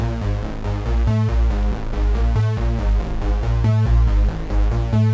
Index of /musicradar/dystopian-drone-samples/Droney Arps/140bpm
DD_DroneyArp3_140-A.wav